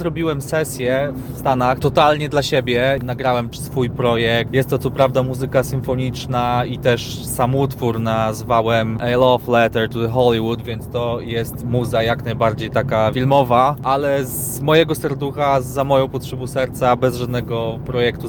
utwór symfoniczny